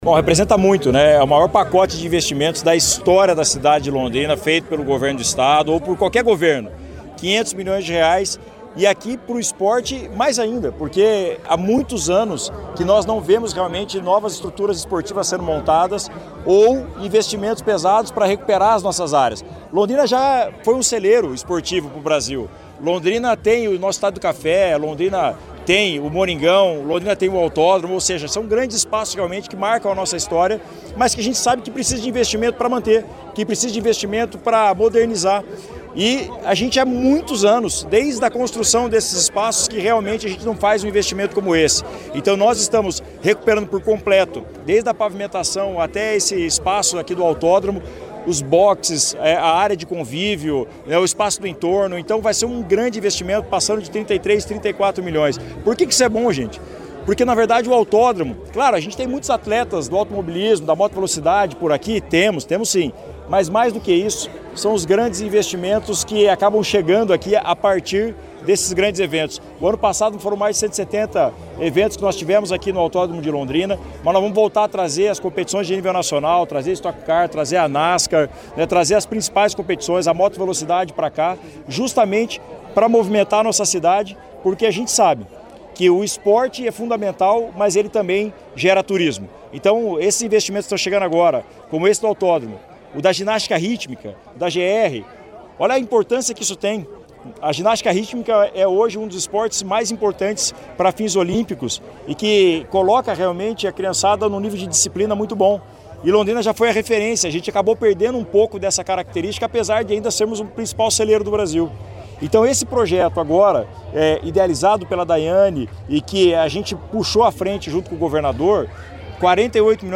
Sonora do prefeito de Londrina, Tiago Amaral, sobre os anúncios feitos para a área esportiva da cidade